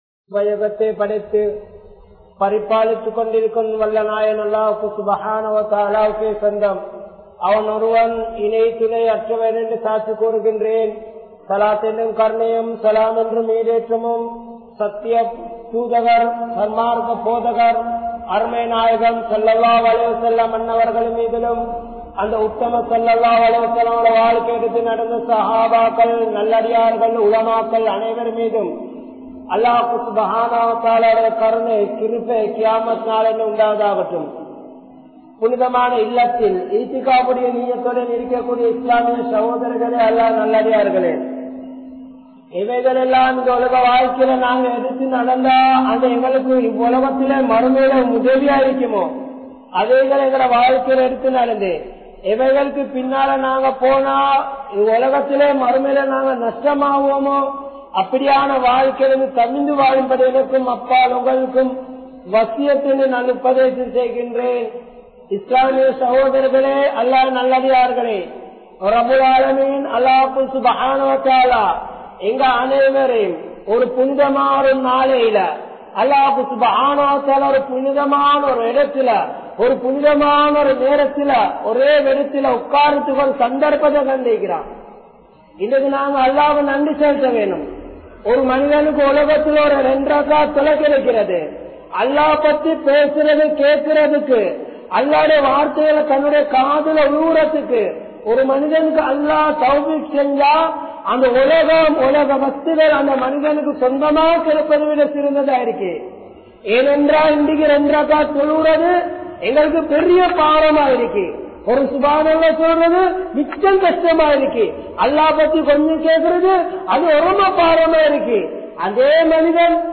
Barakath Entraal Enna? (பரக்கத் என்றால் என்ன?) | Audio Bayans | All Ceylon Muslim Youth Community | Addalaichenai